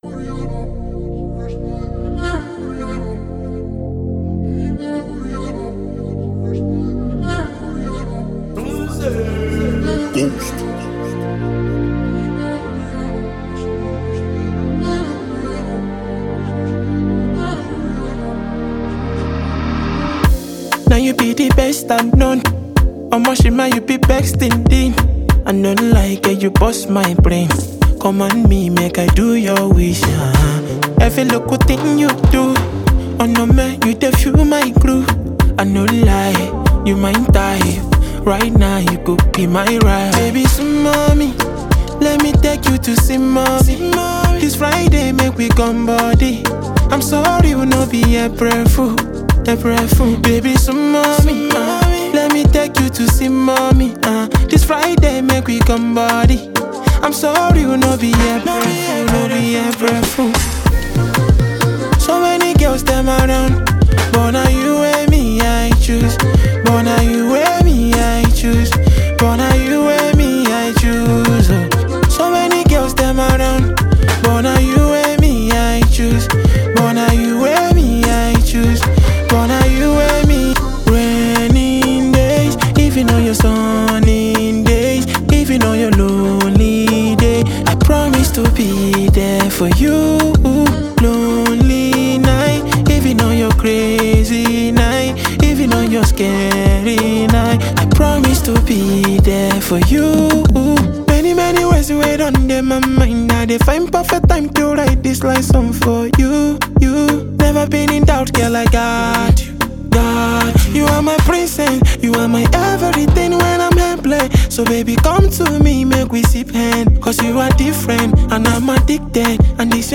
Afrobeat
is a captivating Afrobeat anthem